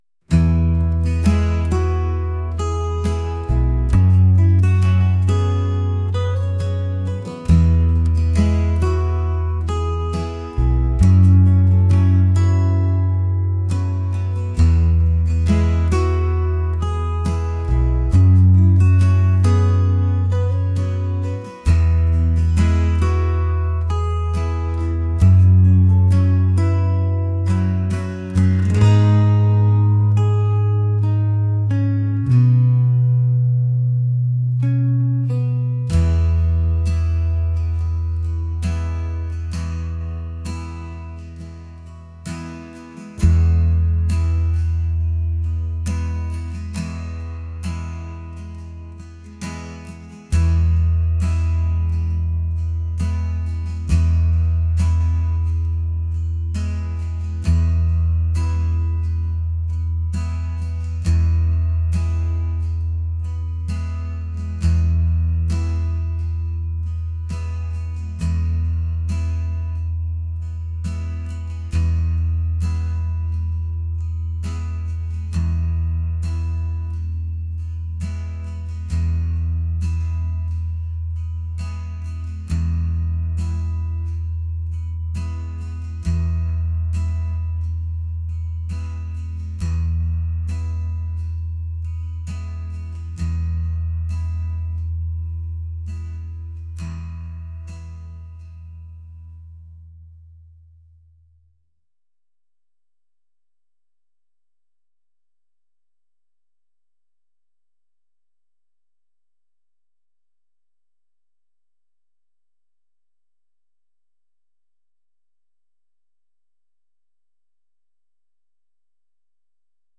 country | acoustic | soulful